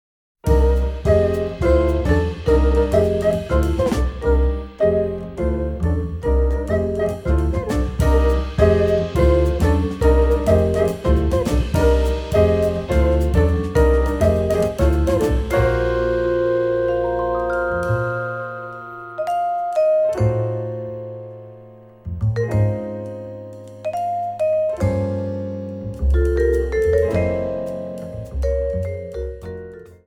サンバのリズムに乗せて